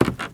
STEPS Wood, Creaky, Walk 07.wav